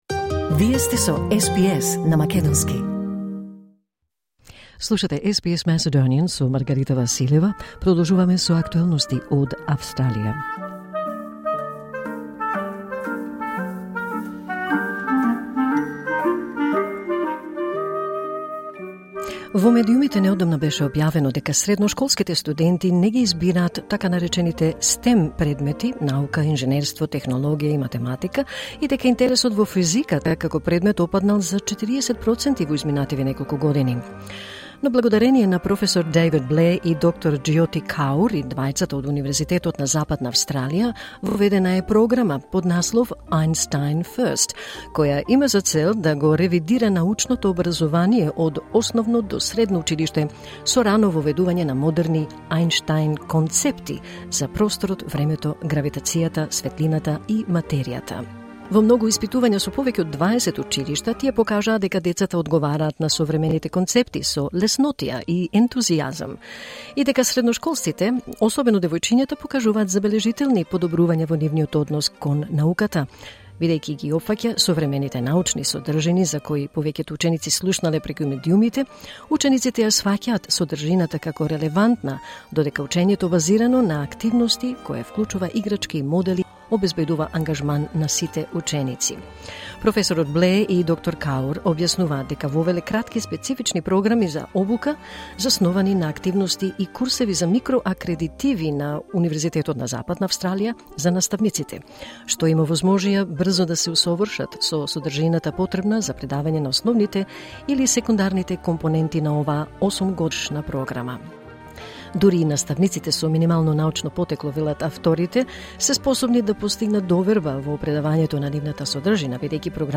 во разговор за СБС на македонски